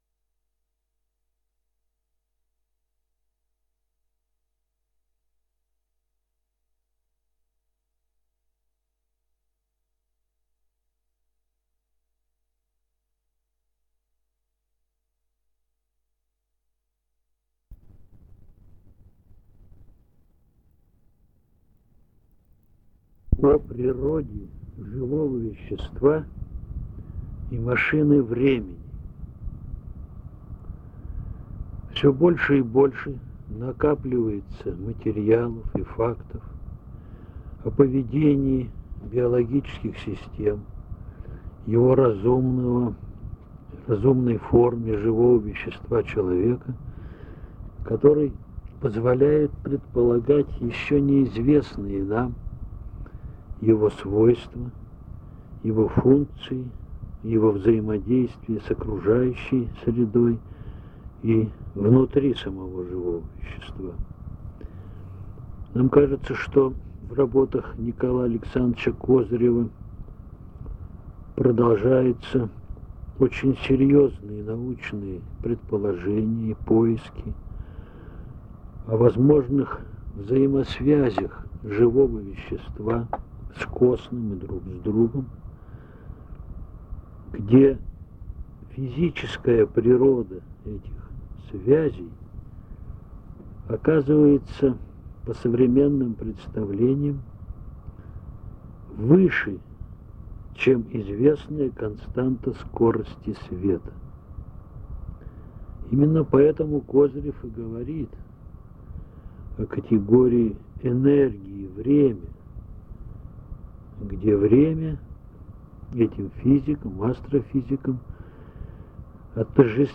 - Устная речь.